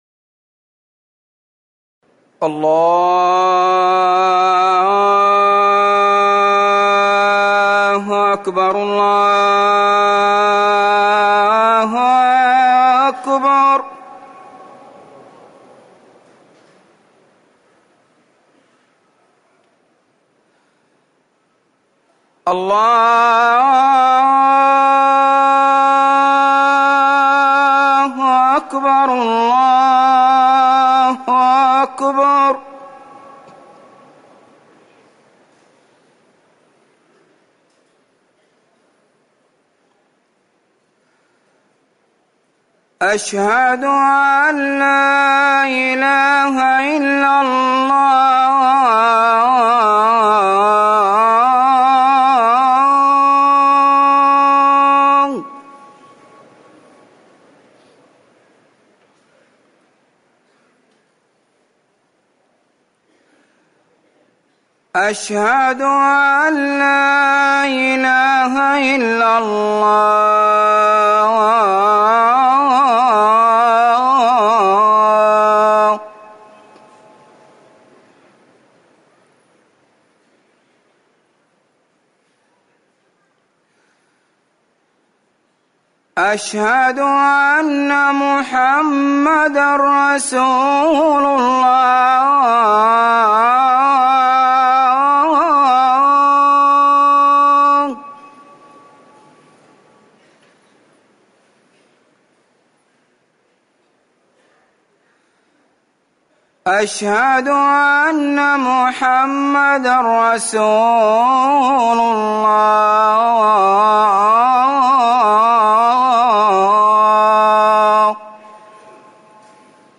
أذان العشاء
تاريخ النشر ٢٩ صفر ١٤٤١ هـ المكان: المسجد النبوي الشيخ